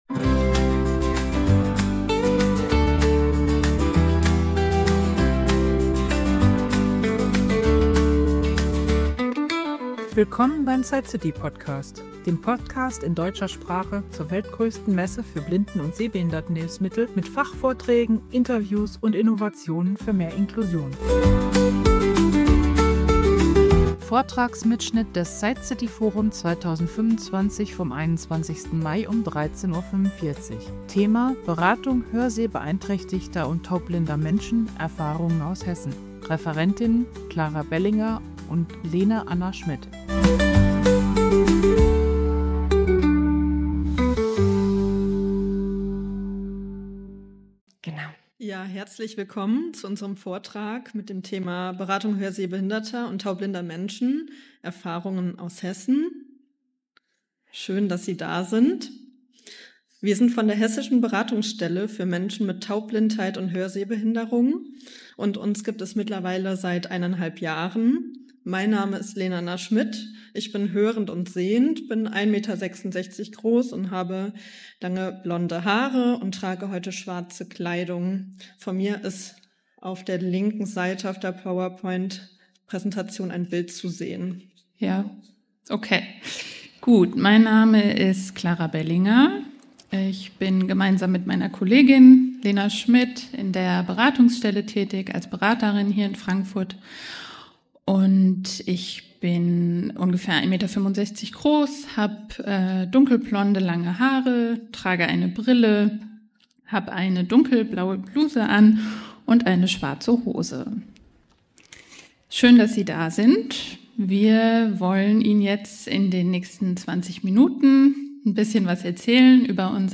Der Vortrag gibt Einblicke in die Herausforderungen und Unterstützungsmöglichkeiten für Betroffene, stellt Kommunikationsformen wie das Lormen vor und informiert über Veranstaltungen, Netzwerke und Zukunftspläne der Beratungsstelle.